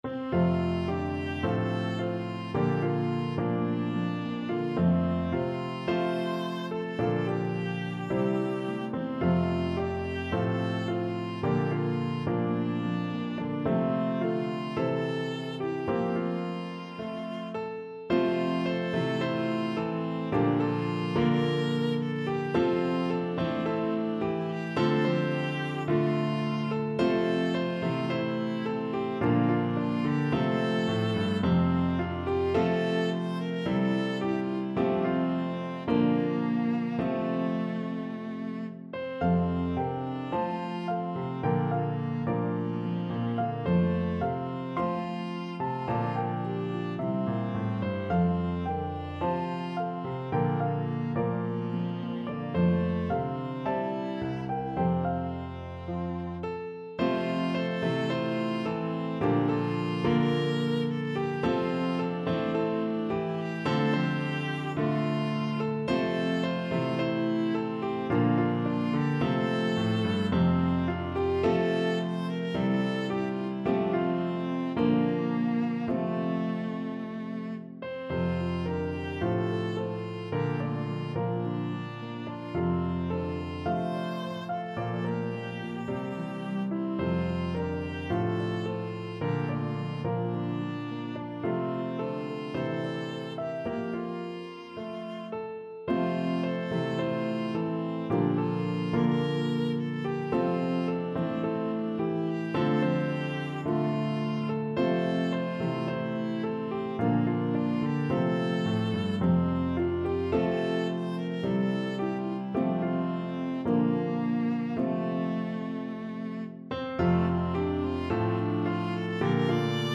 pedal harp
Harp, Piano, and Viola version